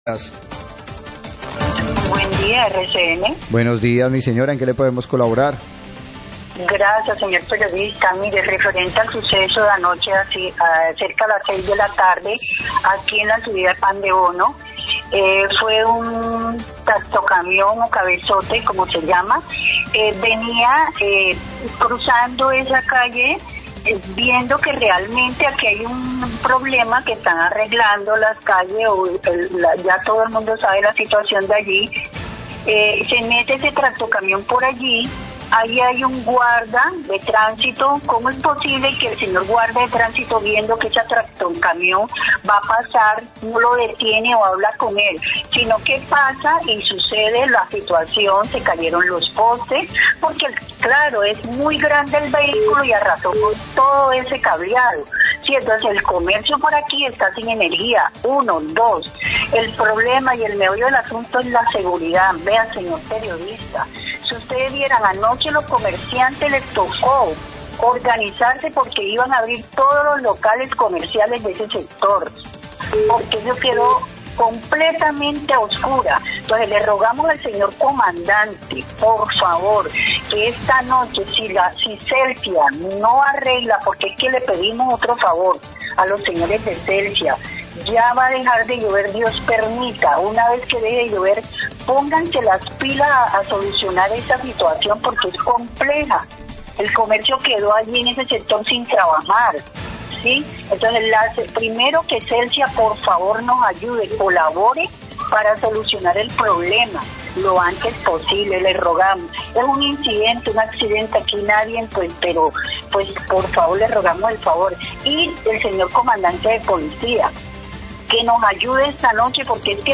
Oyente hace llamado para arreglo de daño ocasionado por tractocamión
Radio